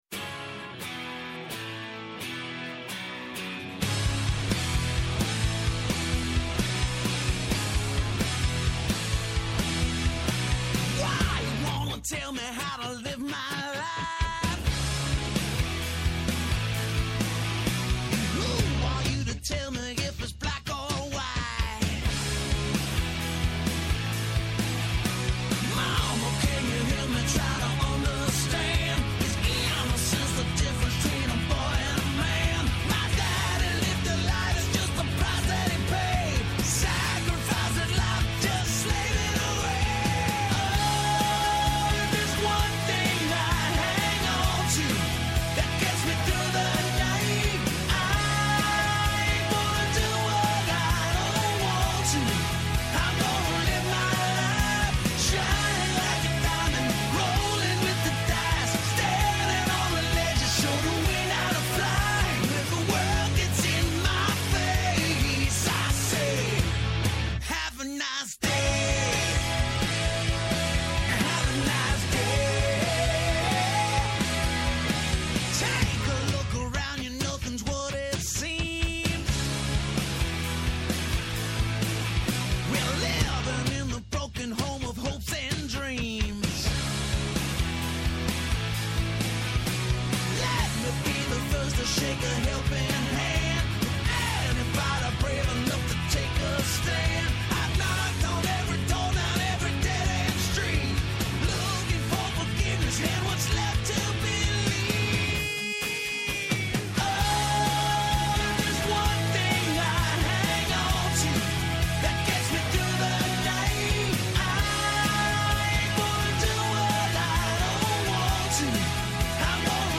Διαιτολόγος-Διαιτροφολόγος Συμφωνούν, διαφωνούν, φωτίζουν και αποκρυπτογραφούν τα γεγονότα με πολύ κέφι, πολλή και καλή μουσική και πολλές εκπλήξεις. Με ζωντανά ρεπορτάζ από όλη την Ελλάδα, με συνεντεύξεις με τους πρωταγωνιστές της επικαιρότητας, με ειδήσεις από το παρασκήνιο, πιάνουν τιμόνι στην πρώτη γραμμή της επικαιρότητας.